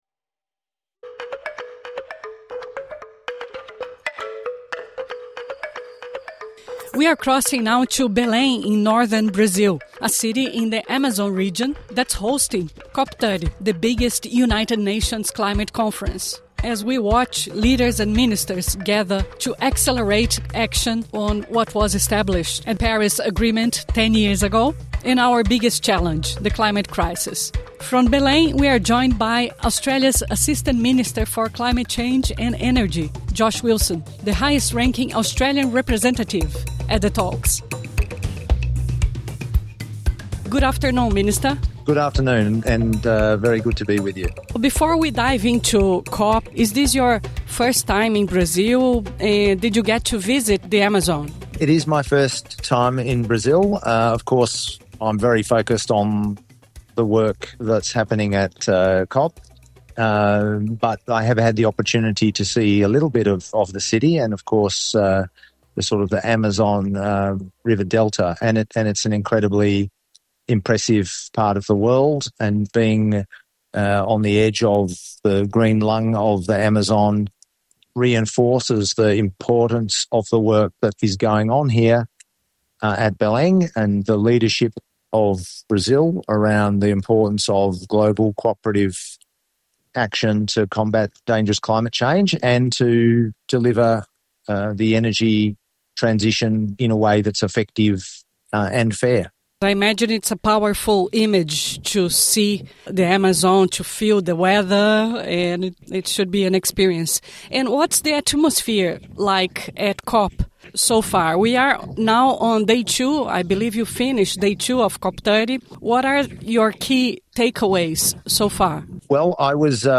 INTERVIEW: SBS Portuguese speaks to Assistant Climate Change Minister Josh Wilson at COP30